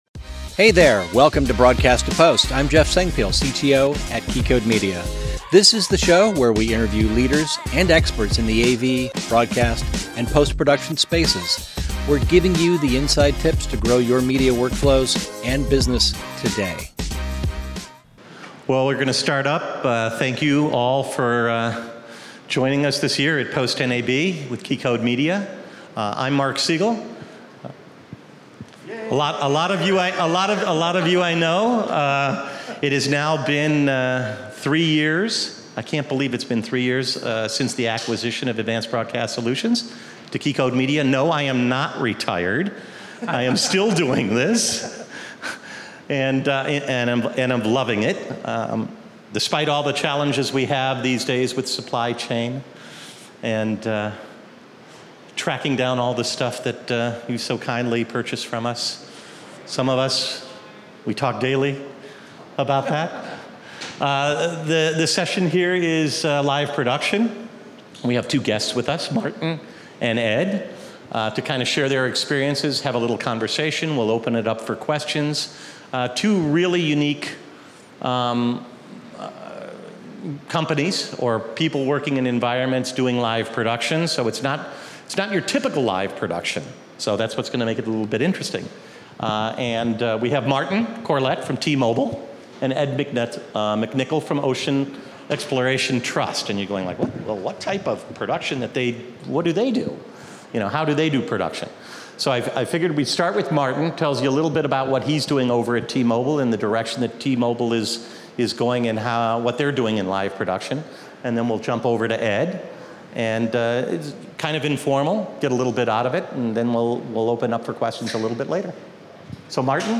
This is a live recording from our PostNAB Seattle event.